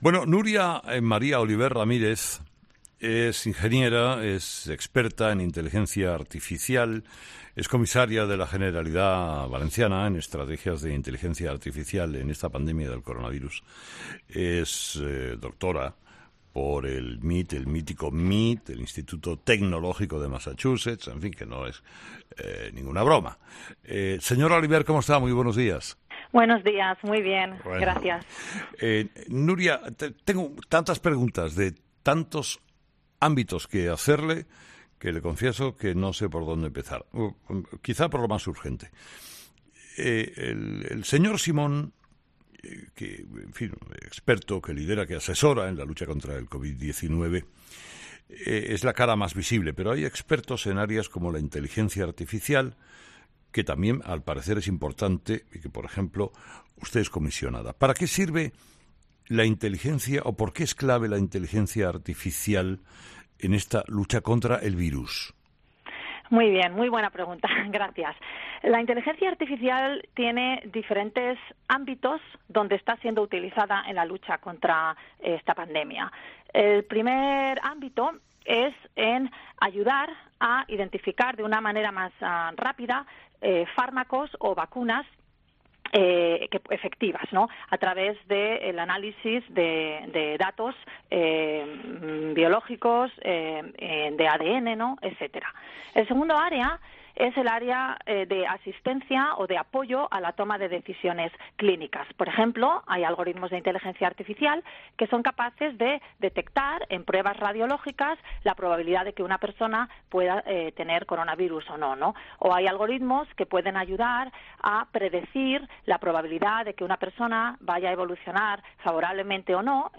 Nuria Oliver, experta en inteligencia artificial y comisionada de la Presidencia para la Estrategia Valenciana para la Inteligencia Artificial, ha sido entrevistada este lunes en 'Herrera en COPE', donde ha dicho que esta disciplina “tiene diferentes ámbitos” de actuación en la lucha contra la pandemia.